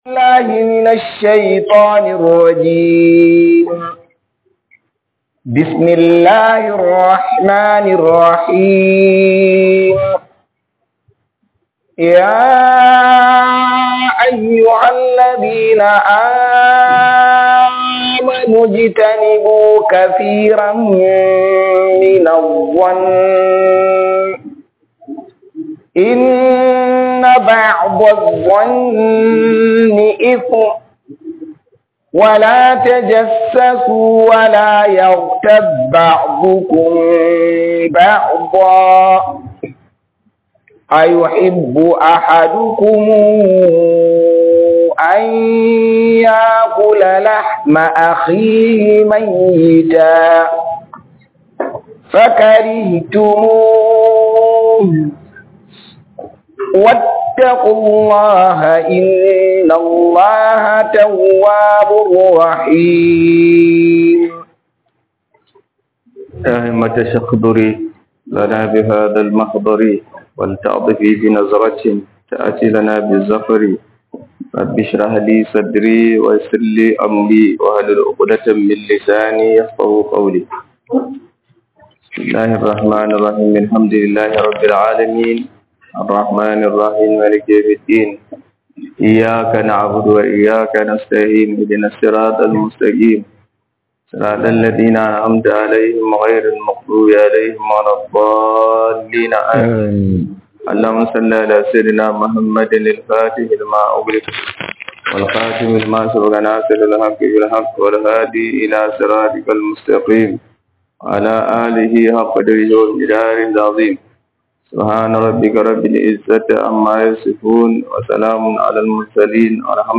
TAFSIR